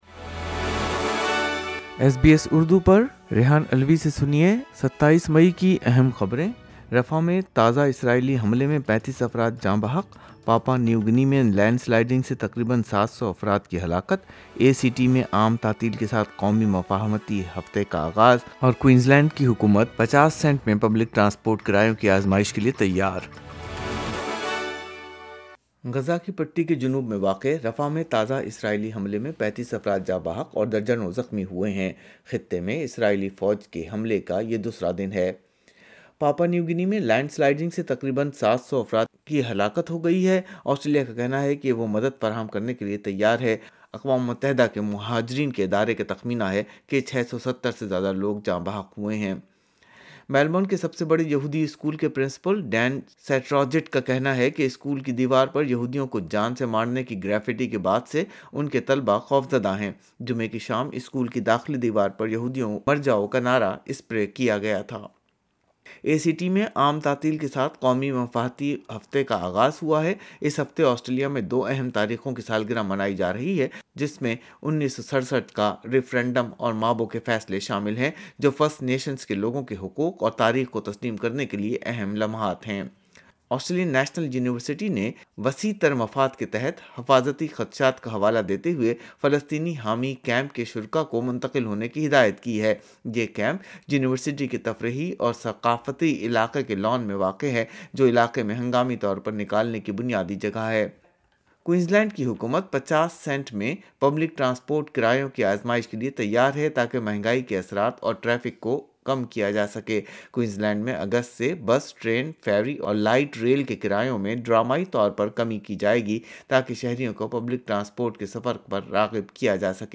نیوز فلیش 27 مئی 2024: پولیس کو آسٹریلین نیشنل یونیورسٹی میں فلسطین حمایتی کیمپ ہٹانے کی حکومتی ہدایت